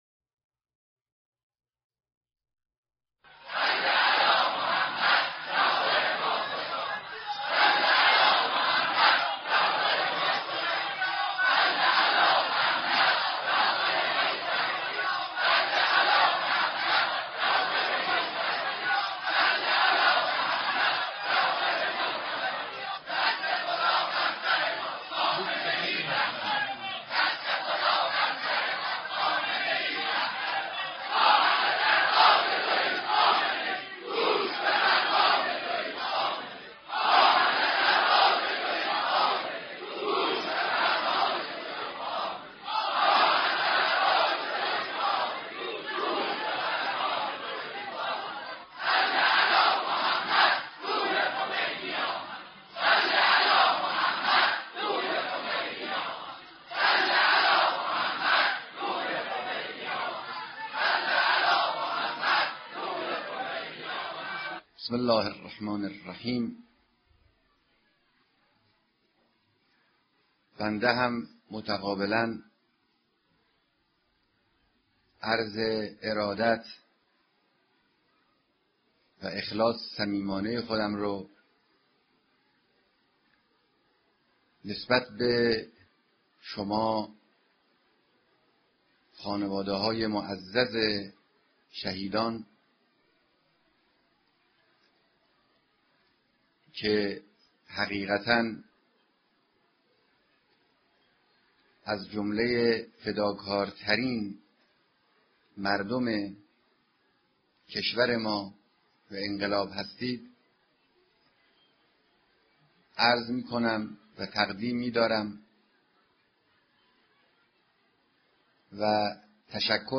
سخنرانی در دیدار با جمع کثیری از جانبازان، خانوادههای معظّم شهدا، اسرا، مفقودان